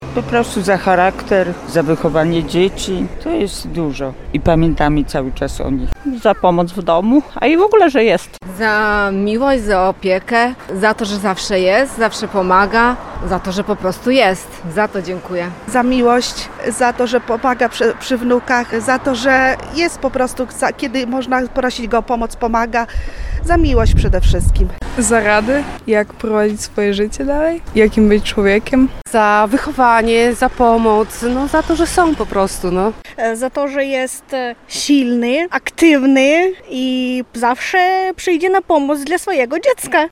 Za co cenimy swoich ojców? Zapytaliśmy o to mieszkańców Ełku.